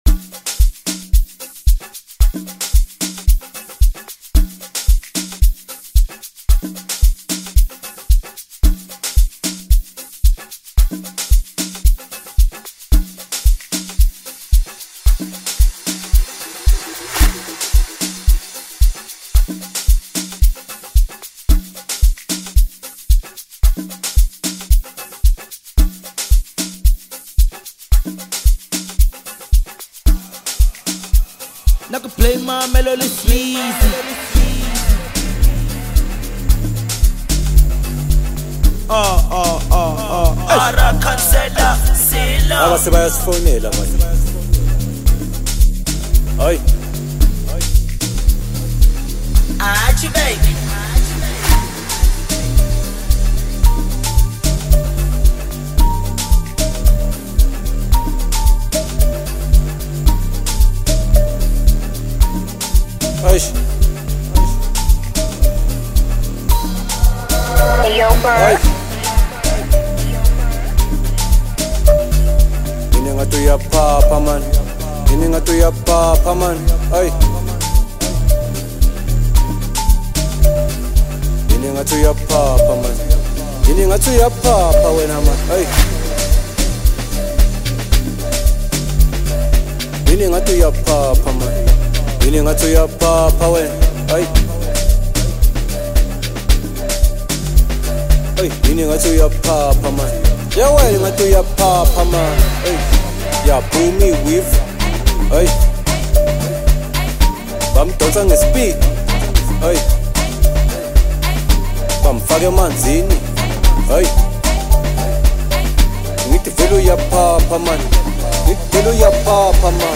Amapiano, DJ Mix, Hip Hop
South African singer-songwriter